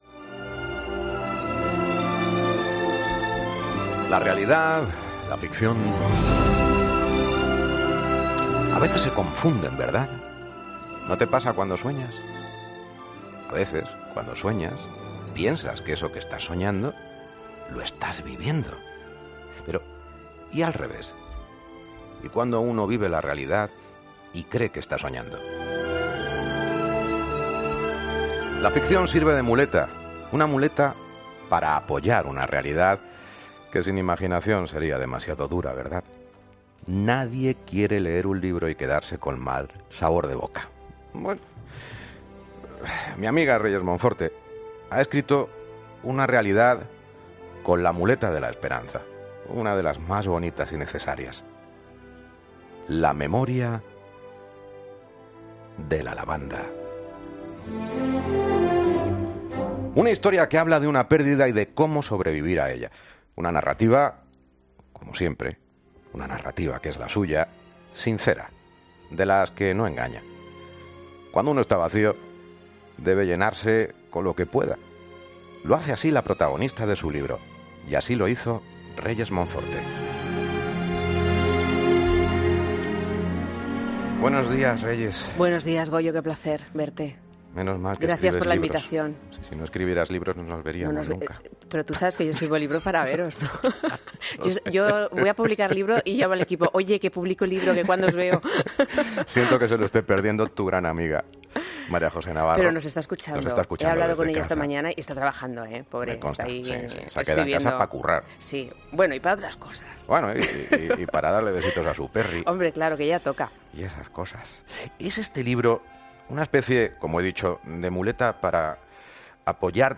Escucha la entrevista Reyes Monforte en Herrera en COPE